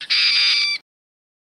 Mink Scream Longer